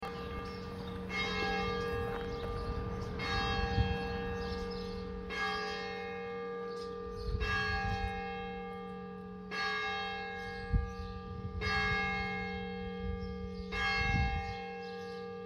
Sonnerie horaire du 20/05/2025 10h00